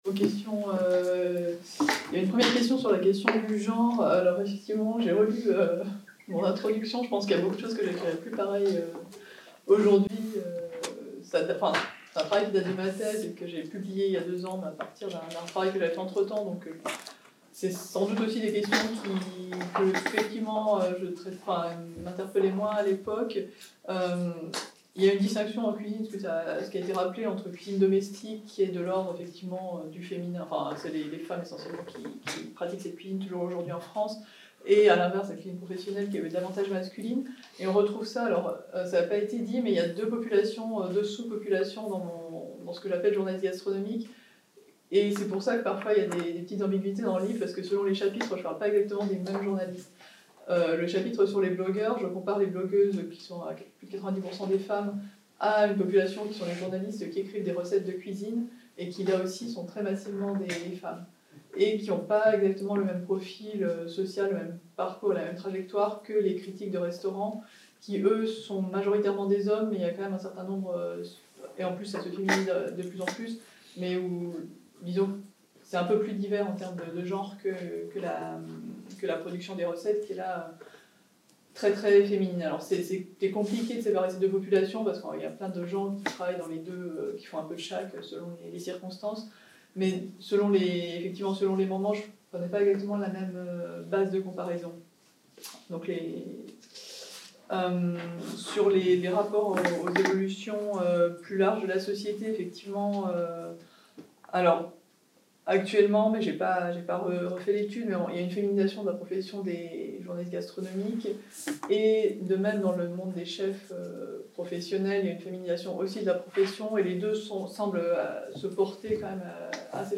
Goût et économie des biens symboliques - discussion | Canal U